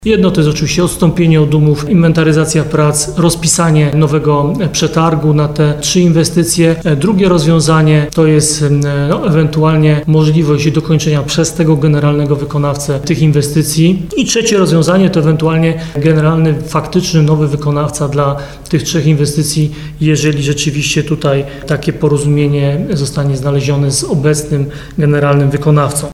– Mamy kilka opcji na rozwiązanie tego problemu – komentuje Ludomir Handzel, prezydent Nowego Sącza.